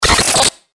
FX-977-BREAKER
FX-977-BREAKER.mp3